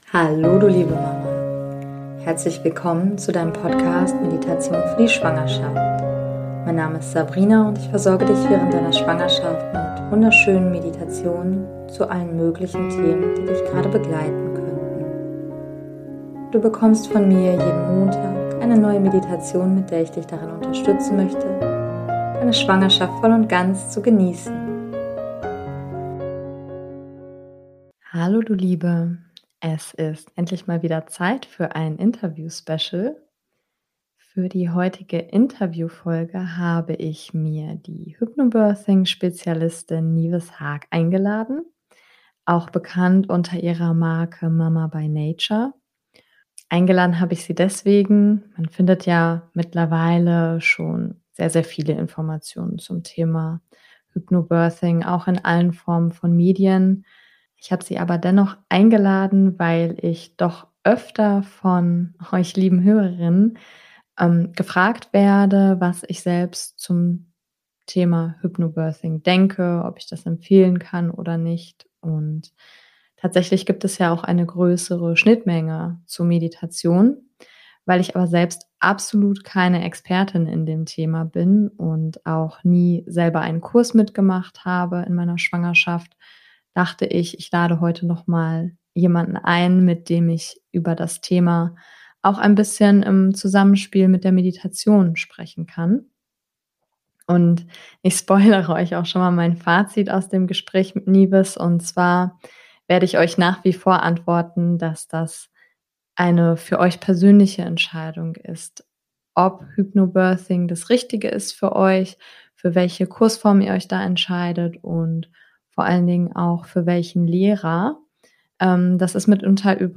Beschreibung vor 4 Jahren Für die heutige Interview Folge